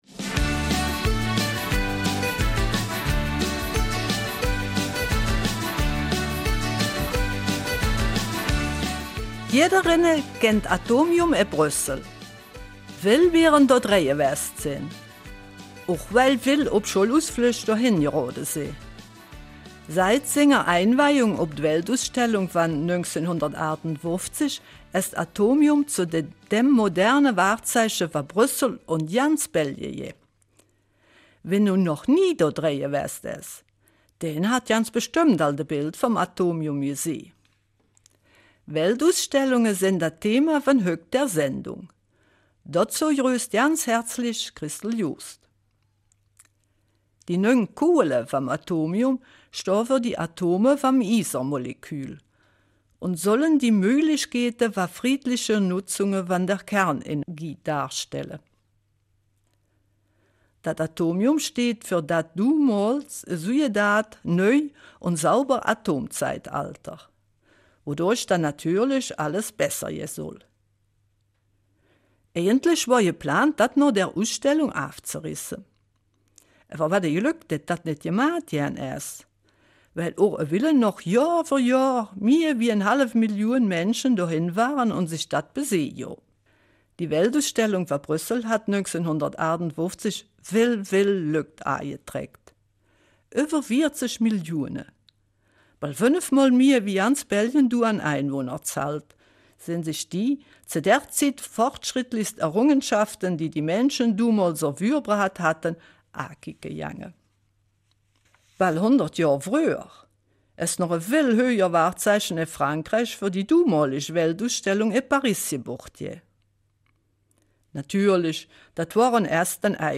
Eifeler Mundart: Weltausstellung in Dubai